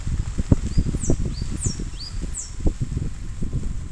Naranjero (Rauenia bonariensis)
Nombre en inglés: Blue-and-yellow Tanager
Localidad o área protegida: Amaicha del Valle
Condición: Silvestre
Certeza: Vocalización Grabada